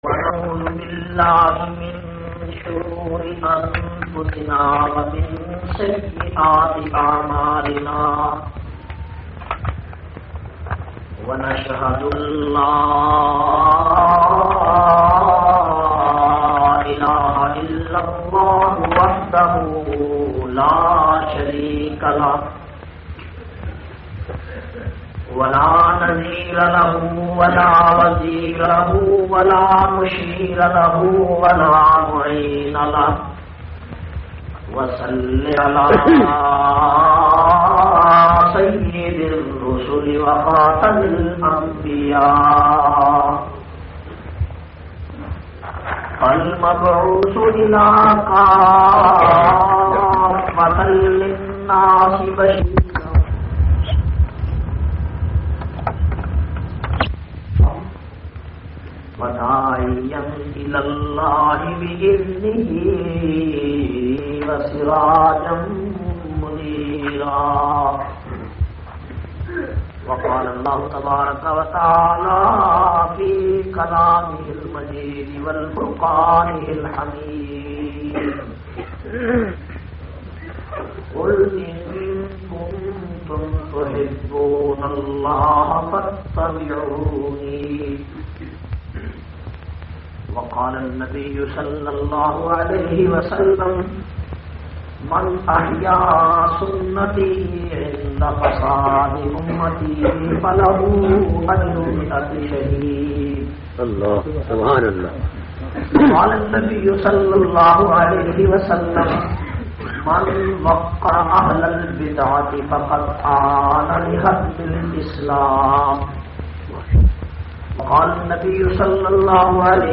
379- Tehreek e Azadi aur Ulma e Deoband Ishq e Rasool Conference Dar al Uloom Taleem ul Furqan Fatah Jung.mp3